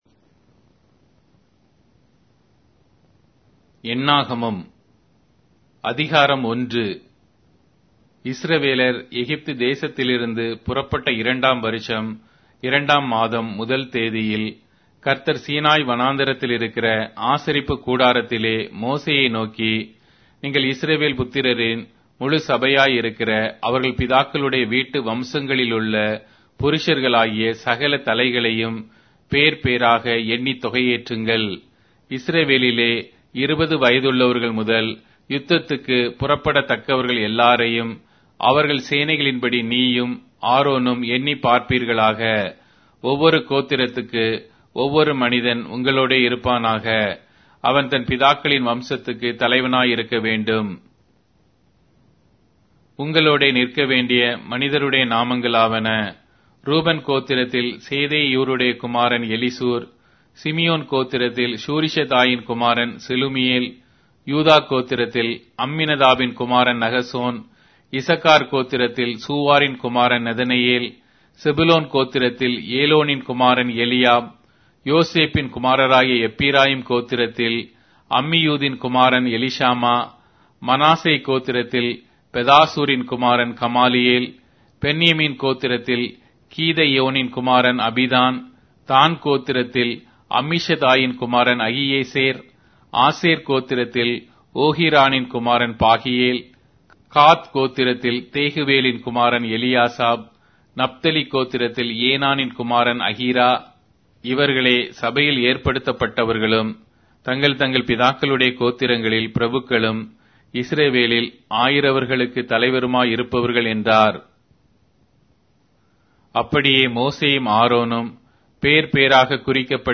Tamil Audio Bible - Numbers 9 in Ervml bible version